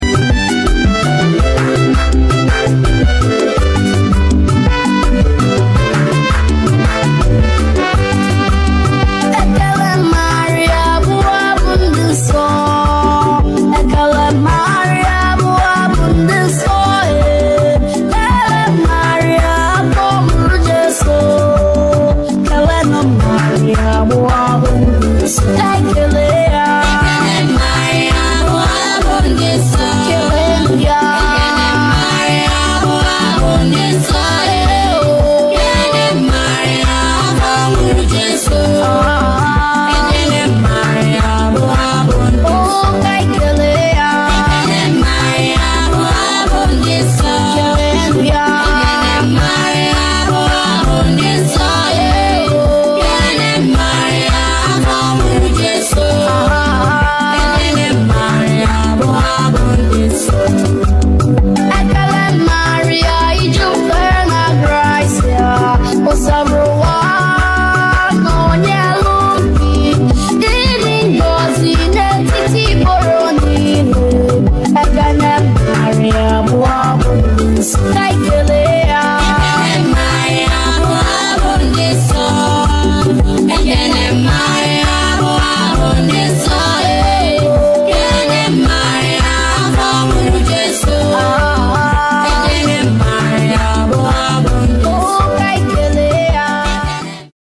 SONG BY : CHILDREN OF MORNING STAR. RECREATED BY IMMACULATE HEART OF MARY BRC, HOLY SPIRIT PARISH, OMAGBA ONITSHA.